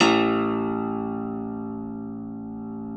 53q-pno01-A-1.wav